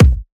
edm-kick-71.wav